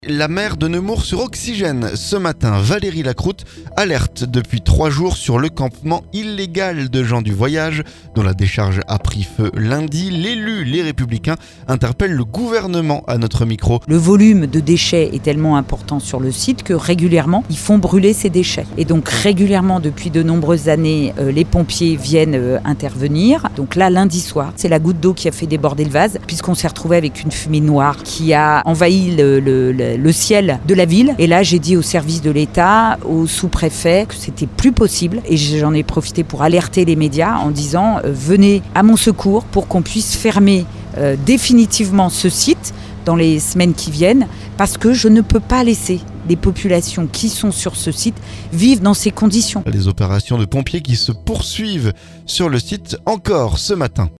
La maire de Nemours sur Oxygène ce jeudi. Valérie Lacroute alerte depuis 3 jours sur le campement illégal de gens du voyage dont la décharge a pris feu lundi... L'élue LR interpelle le gouvernement à notre micro.